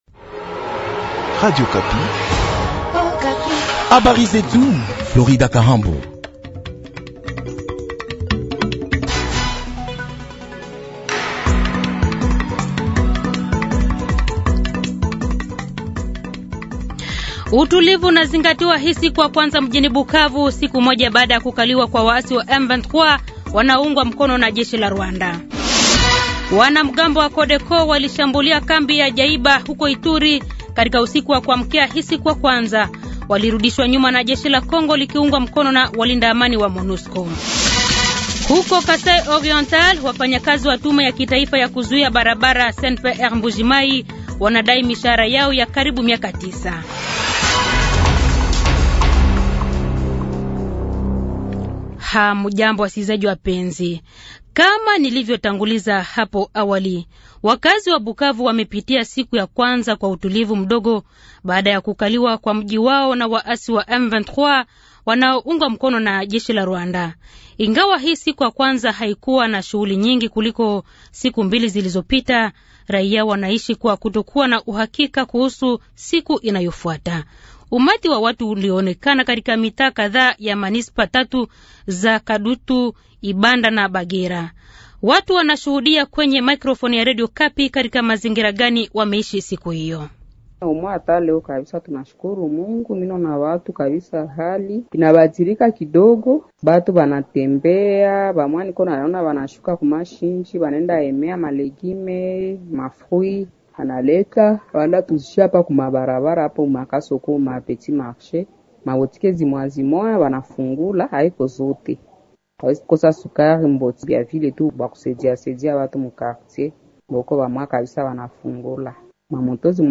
Journal Soir
Habari za jioni 17 Februari, 2025